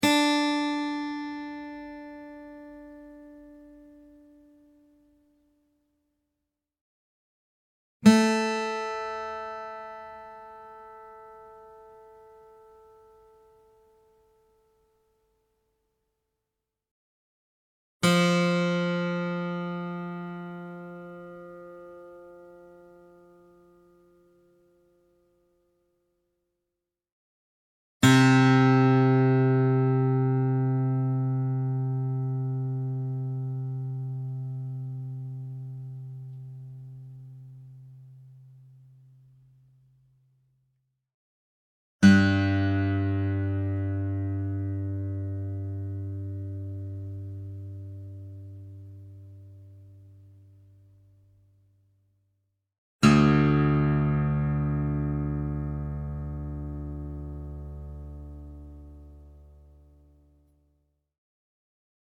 Real acoustic guitar sounds in Full Step Down Tuning
Guitar Tuning Sounds